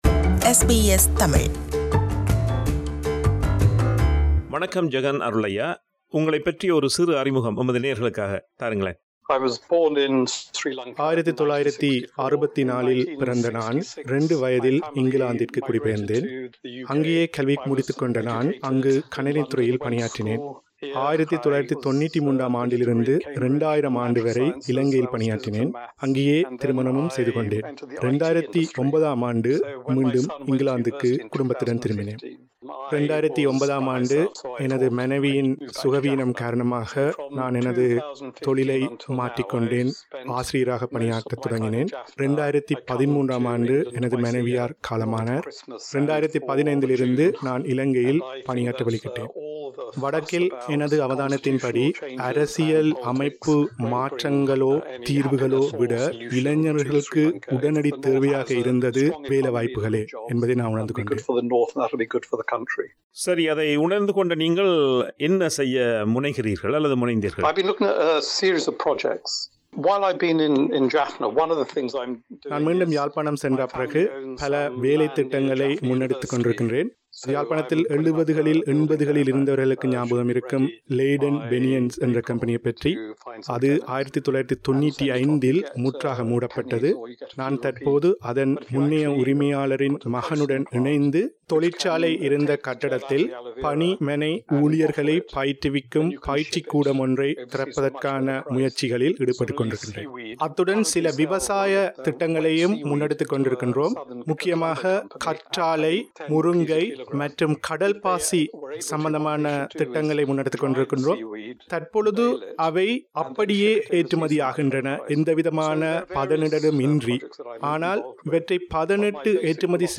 ஆகியோரை நேர்காண்கிறார்
ஆங்கிலத்தில் வழங்கிய பதில்களுக்குத் தமிழில் குரல் தருகிறார்